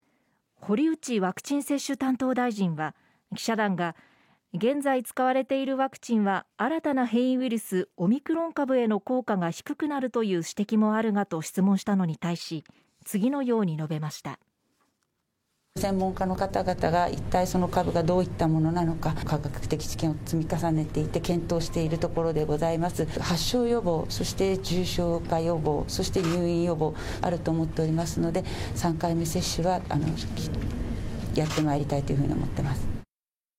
現に、オミクロン株に対して効果が低くなるのでは？と問われた堀内ワクチン接種担当大臣は、少ししどろもどろ的になりながら、次のように答えています（12月1日のNHKラジオ第一放送のニュースより、太字は当記事引用による）。